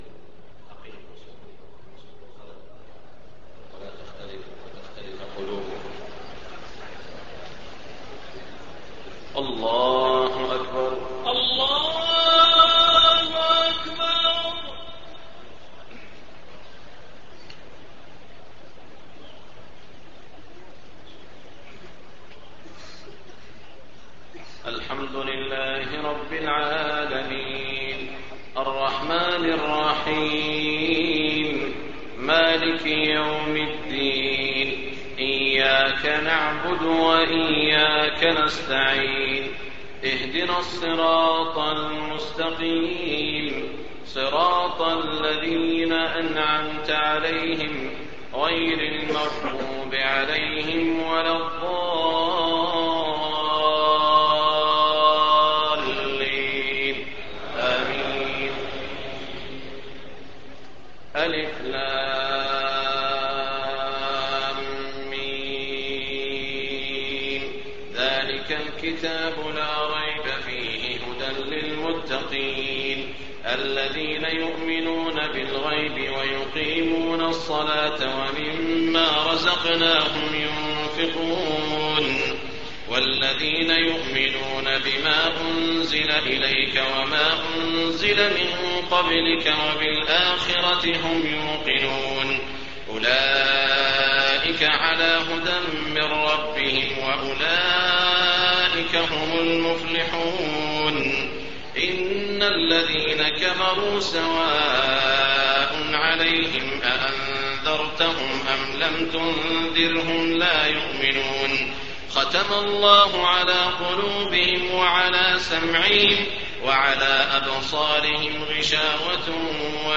تهجد ليلة 21 رمضان 1424هـ من سورة البقرة (1-91) Tahajjud 21 st night Ramadan 1424H from Surah Al-Baqara > تراويح الحرم المكي عام 1424 🕋 > التراويح - تلاوات الحرمين